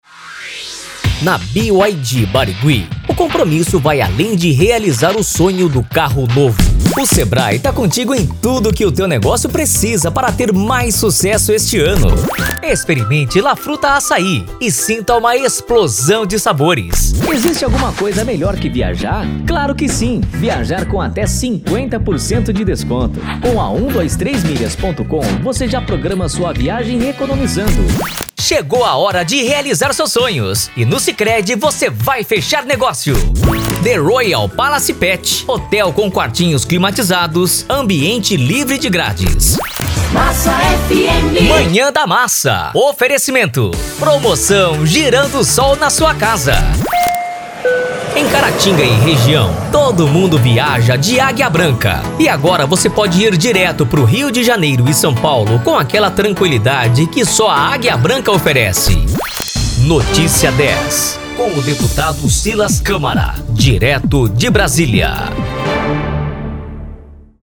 Spot Comercial
Vinhetas
Animada